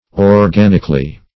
Organically \Or*gan"ic*al*ly\, adv.